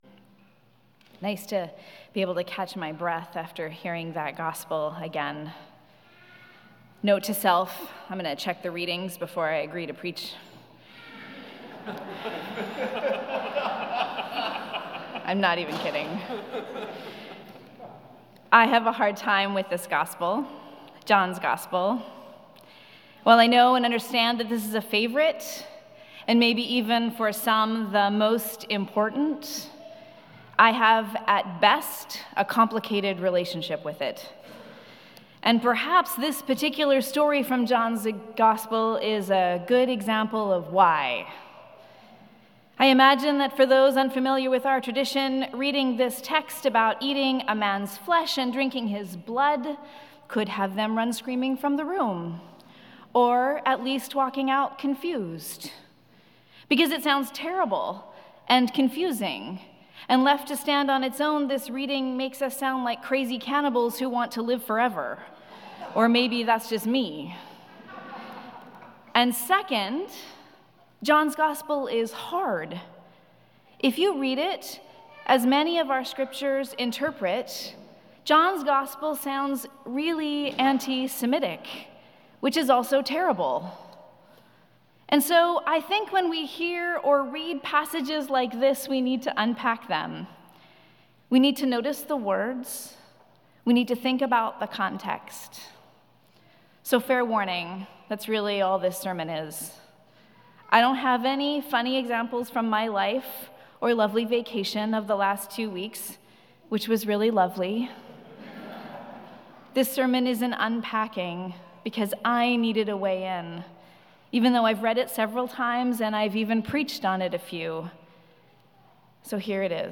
Sermons | Christ Church Cathedral Vancouver BC